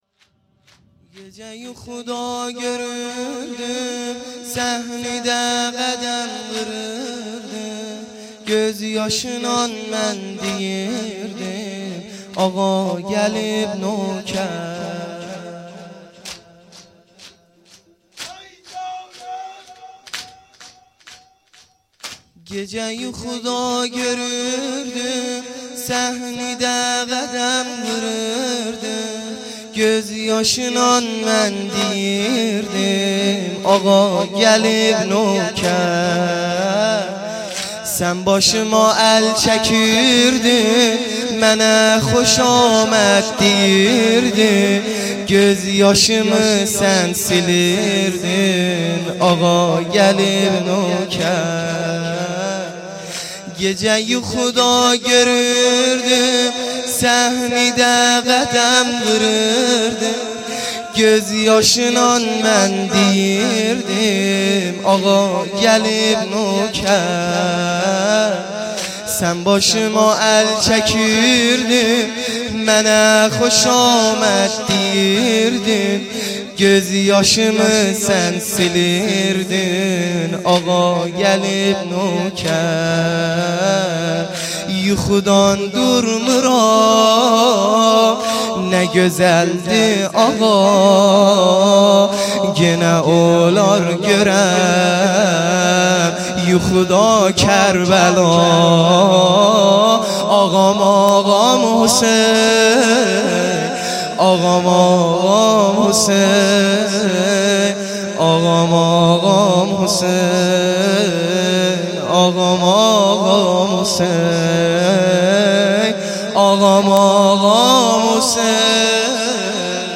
شورهای احساسی